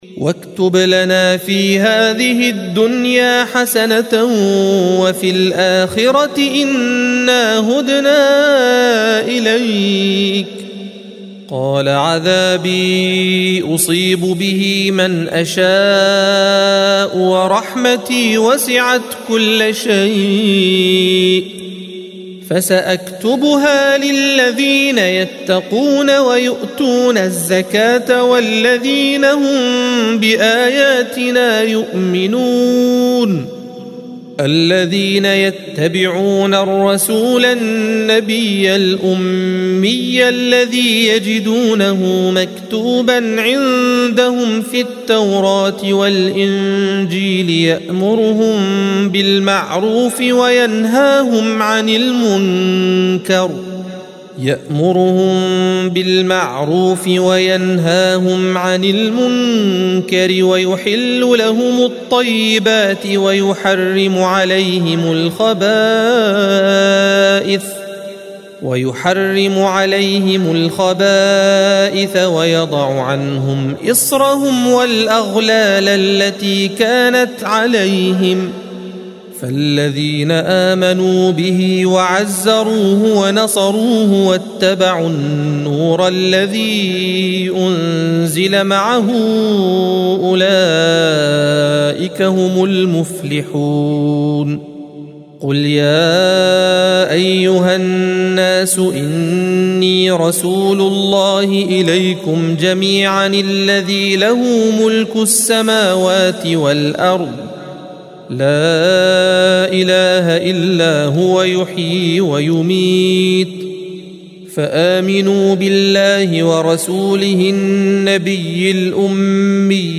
الصفحة 170 - القارئ